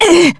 Lucikiel_L-Vox_Damage_jp_02.wav